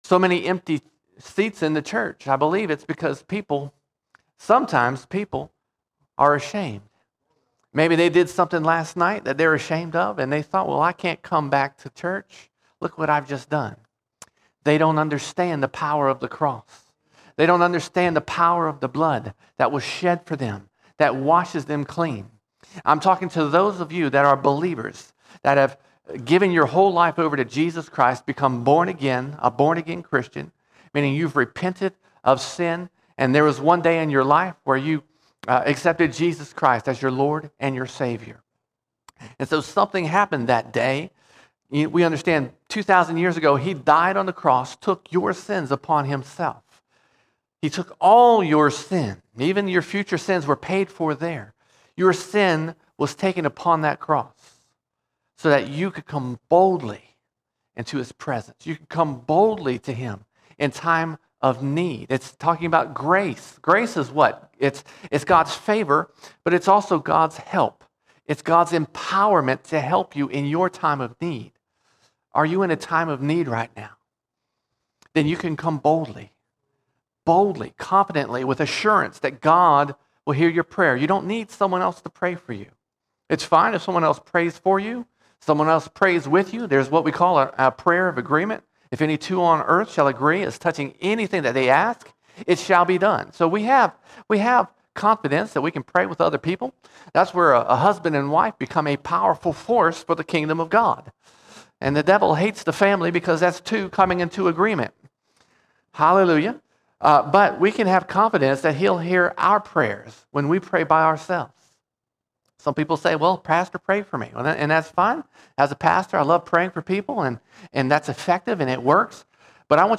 11 December 2023 Series: Sunday Sermons Topic: prayer All Sermons Embracing Fearless Prayer Embracing Fearless Prayer We should approach God with boldness in our time of need.